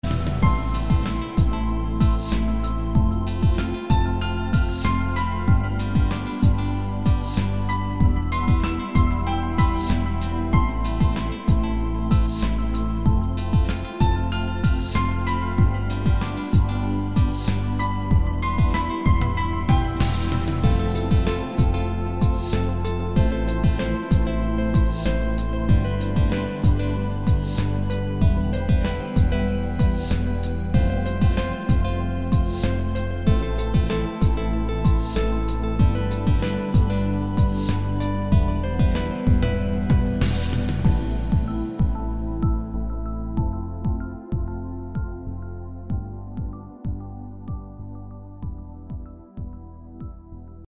• Качество: 256, Stereo
душевные
инструментальные
Original mix